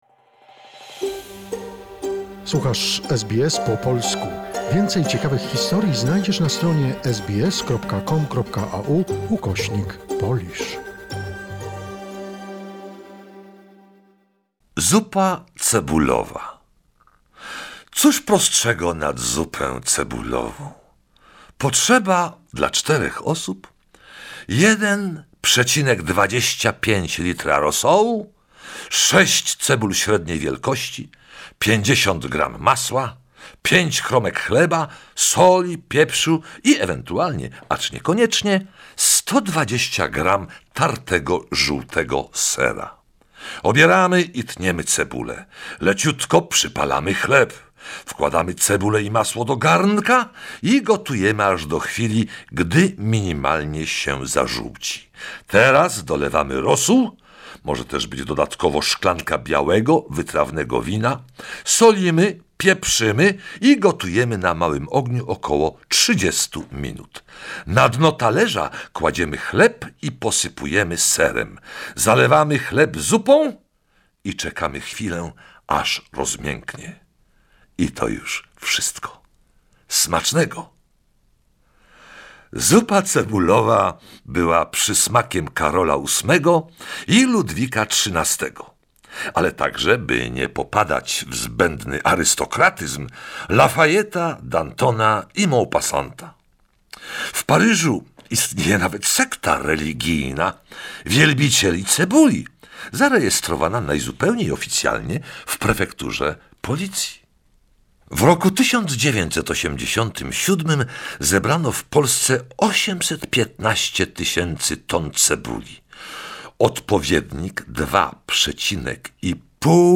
Apparently Stanislas Leszczyński, the father of Marie Leszczyńska (queen of France, married to Louis 15) was so obsessed with the smell. 'Onion soup' is one of the stories from the book “Żywoty zdań swawolnych” by the anthropologist Prof. Ludwik Stomma. We present archival recordings of fragments of the book